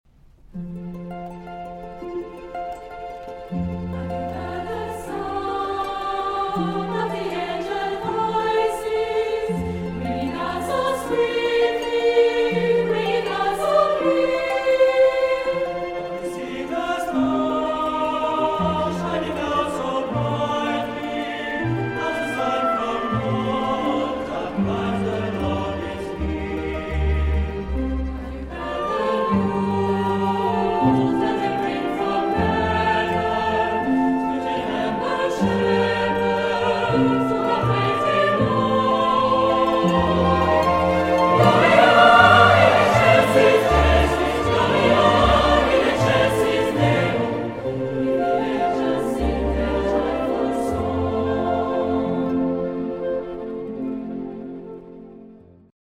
Extraits du concert